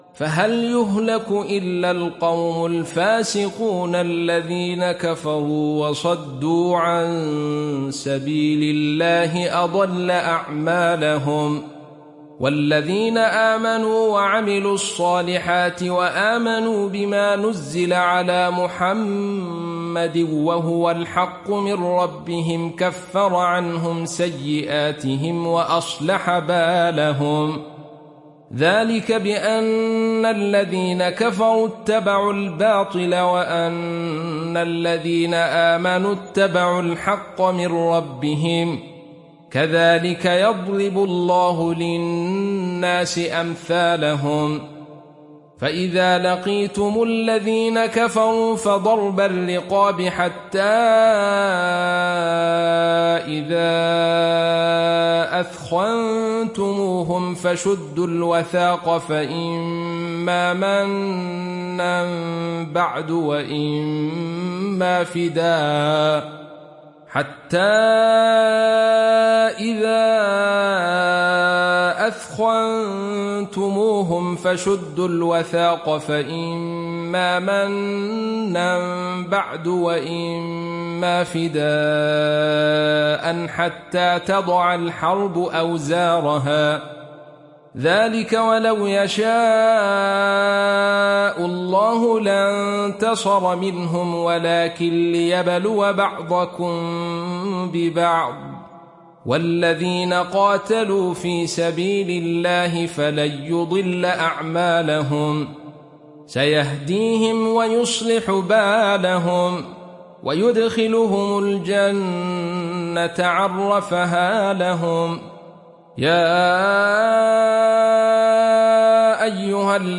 دانلود سوره محمد mp3 عبد الرشيد صوفي روایت خلف از حمزة, قرآن را دانلود کنید و گوش کن mp3 ، لینک مستقیم کامل